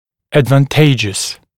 [ˌædvən’teɪʤəs][ˌэдвэн’тэйджэс]благоприятный, выгодный, полезный